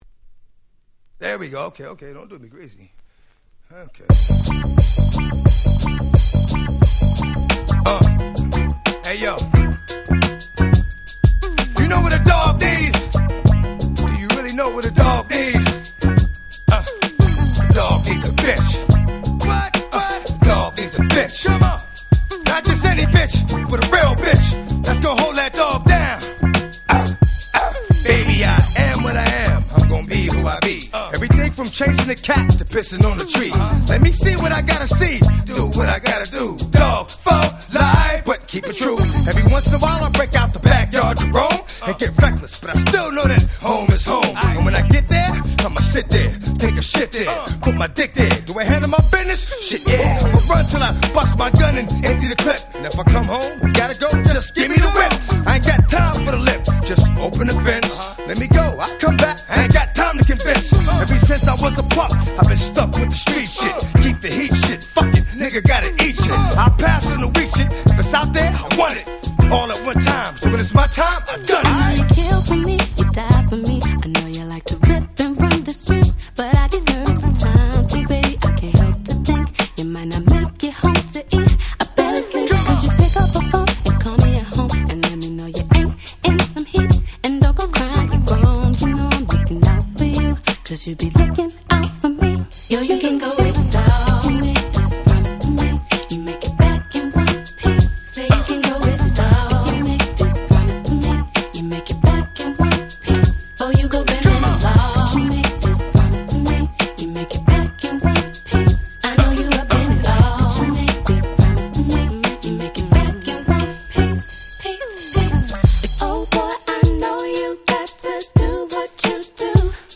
00' Nice R&B / Hip Hop Soul !!